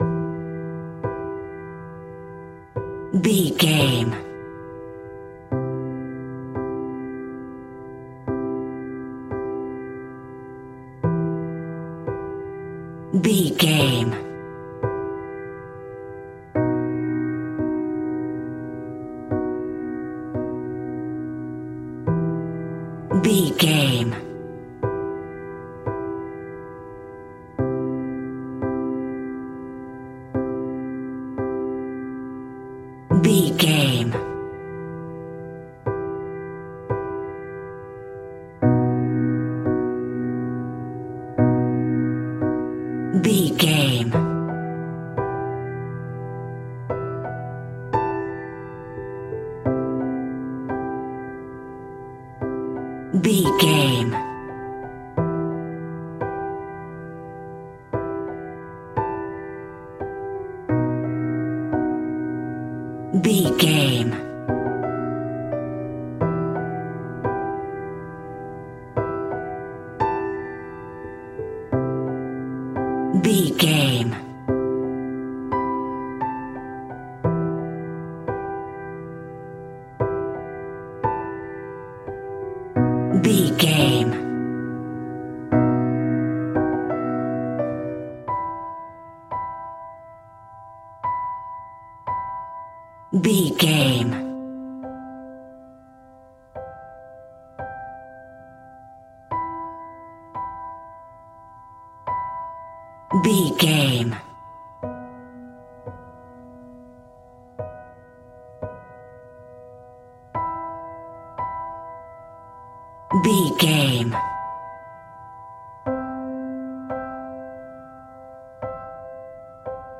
Simple and basic piano music in a major key.
Regal and romantic, a classy piece of classical music.
Aeolian/Minor
regal
romantic
soft